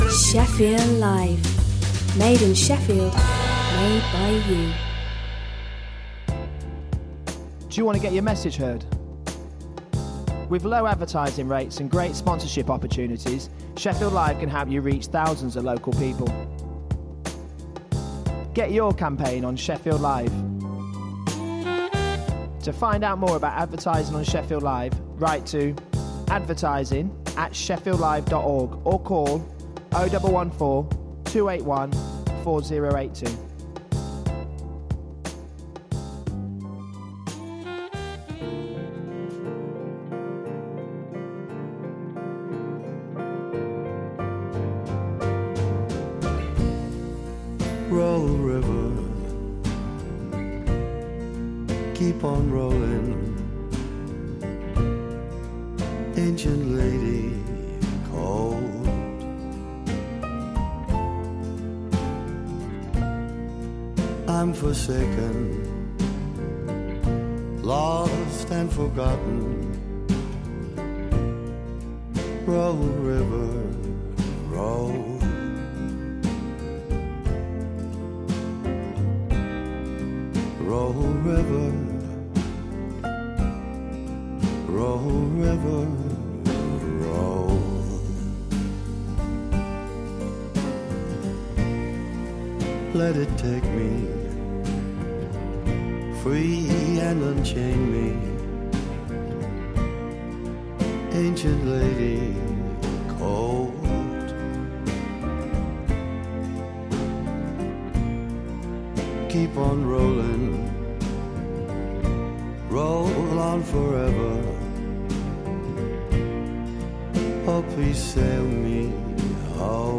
Business news, debate and interviews for anyone interested in growing or starting a business.